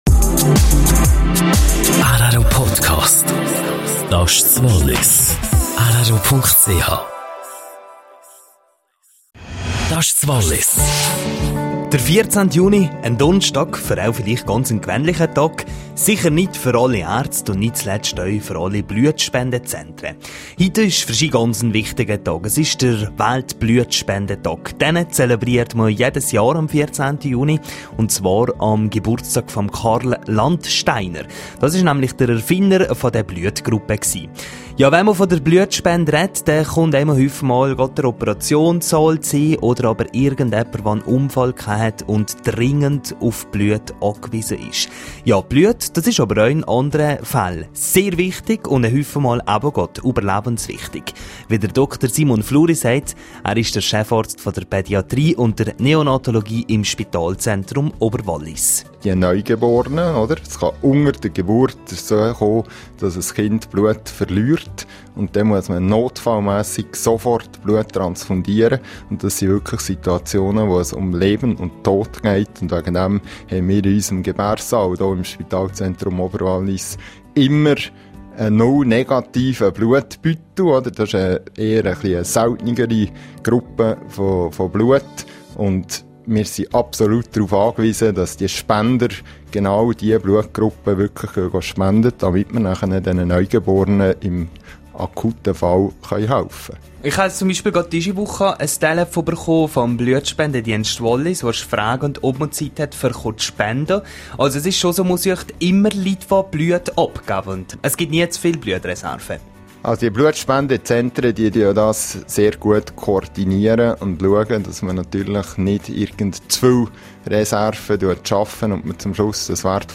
Weltblutspendetag: Interview